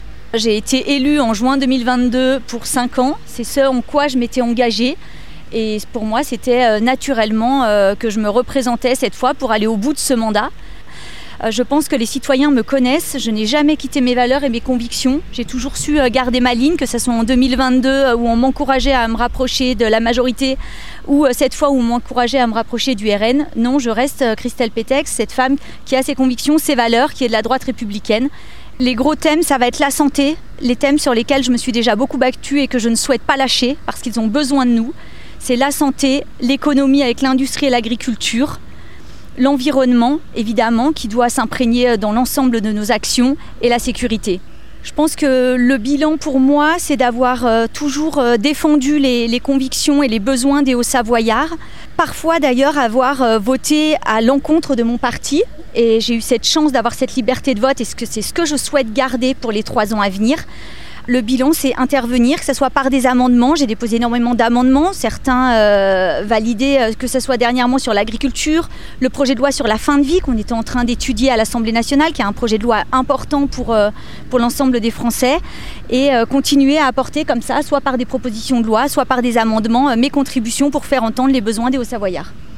Voic les interviews des 5 candidats de cette 3eme circonscription de Haute-Savoie (par ordre du tirage officiel de la Préfecture) et tous les candidats en Haute-Savoie et en Savoie.